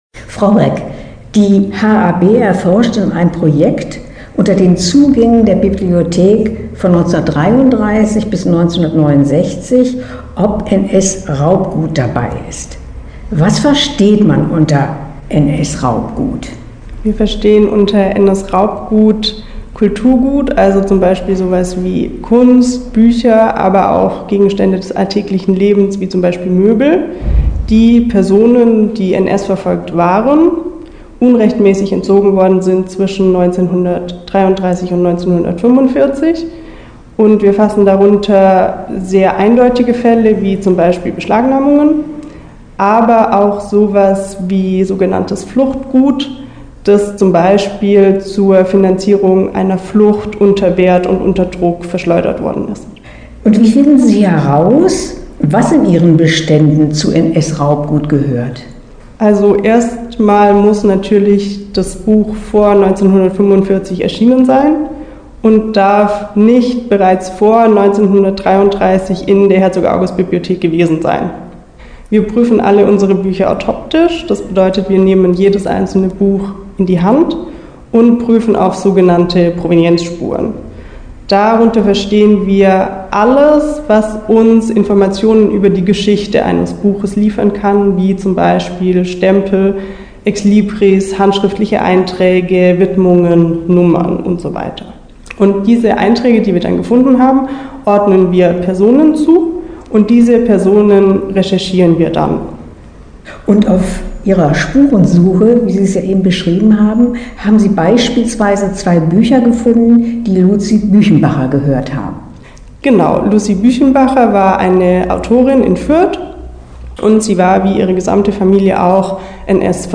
Interview-HAB-NS-Raubgut.mp3